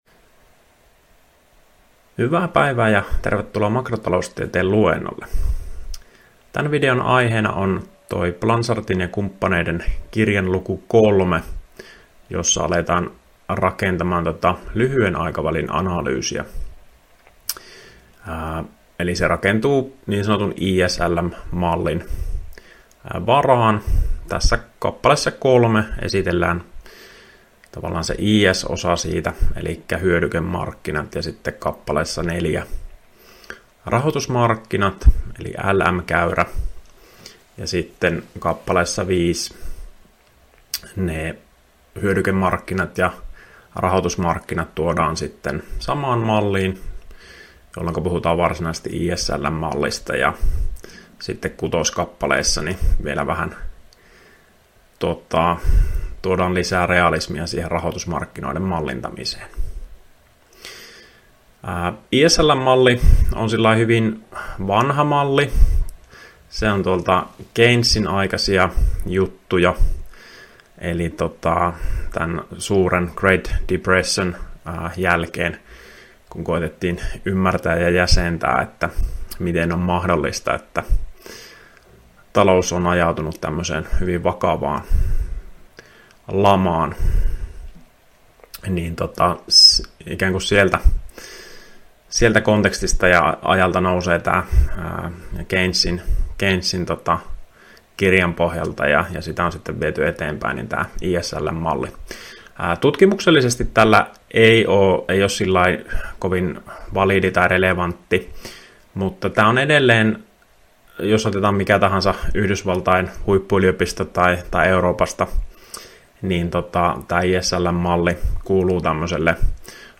Opintojakson "Makrotaloustiede I" suhdanneosion 2. opetusvideo.